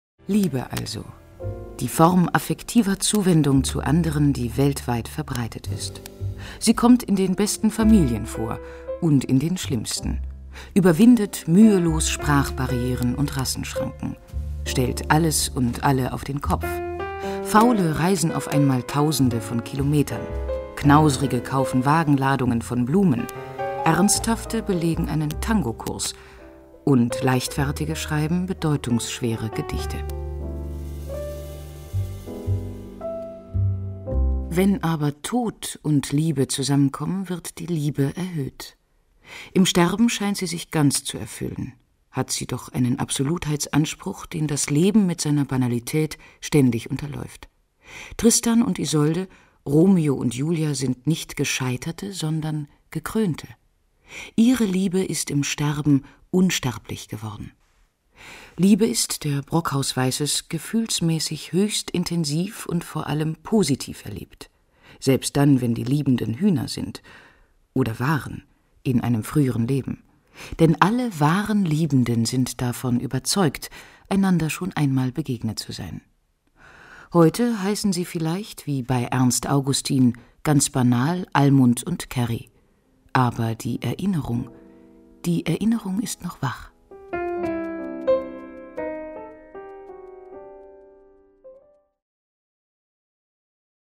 Erfahrene Sprecherin, ausgebildete Schauspielerin ( Universität der Künste - Berlin ), Hörspiel, Feature, Dokumentationen, Audioguides, Audiodeskriptionen, E-Learnings, Werbung und wirklich Vieles mehr ... also alles, was Spaß macht!
deutsch
Sprechprobe: Sonstiges (Muttersprache):